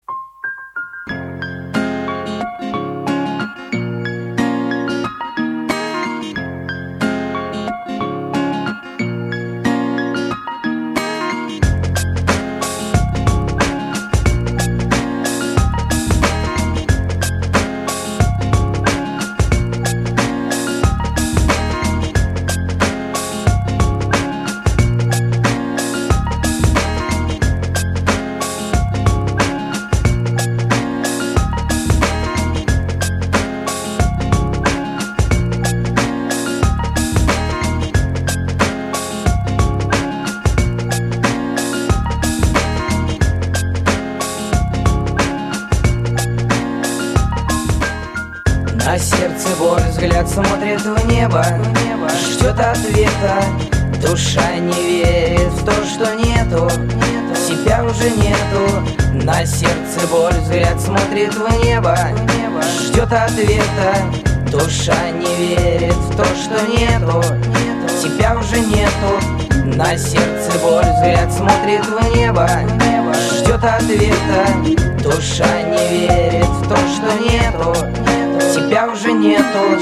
• Качество: 128, Stereo
гитара
грустные
русский рэп
инструментальные
печальные